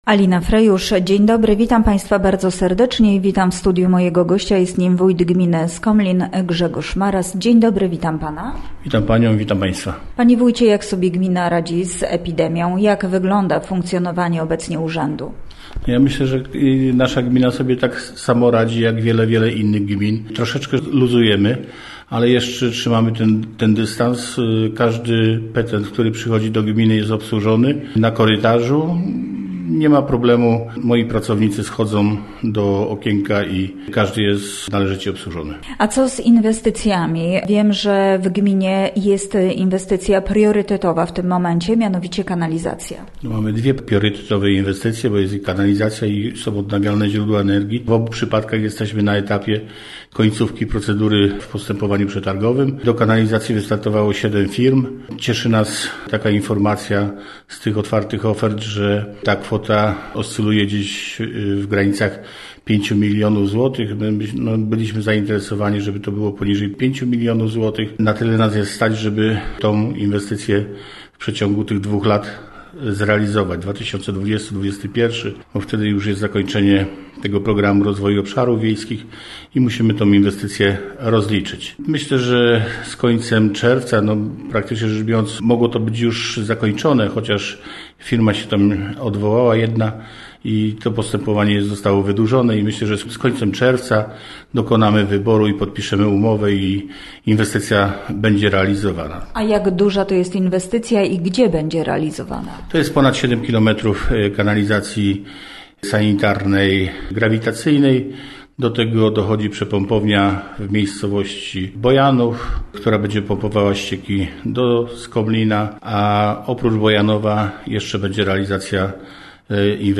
Gościem Radia ZW był Grzegorz Maras, wójt gminy Skomlin